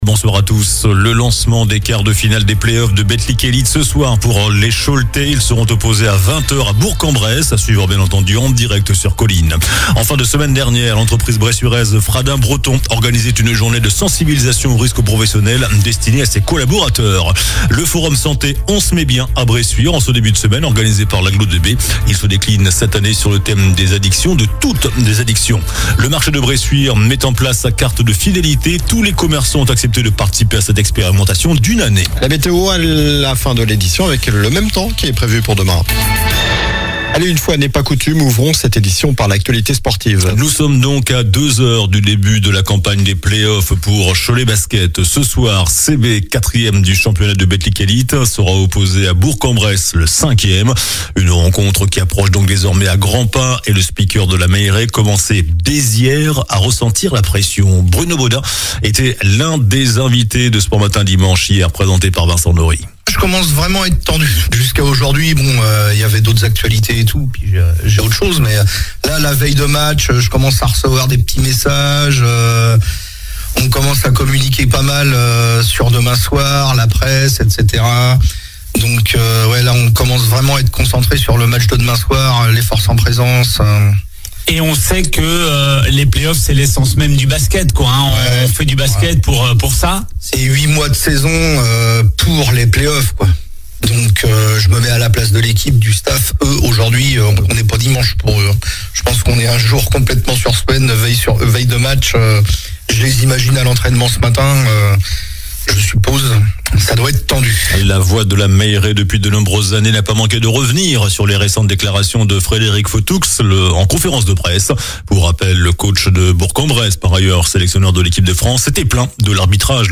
JOURNAL DU LUNDI 26 MAI ( SOIR )